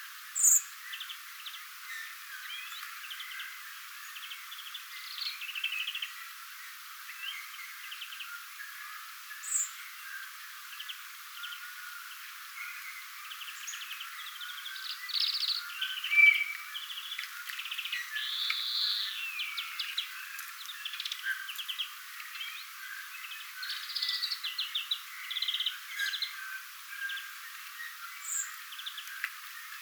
videon rastaan ääniä, oletettavasti
oletettavasti_videon_rastaan_aania.mp3